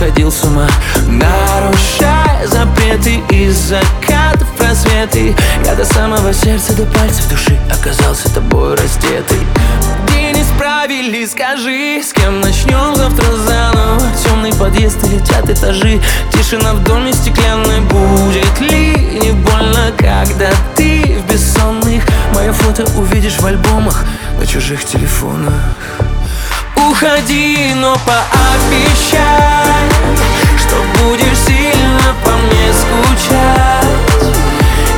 Pop in Russian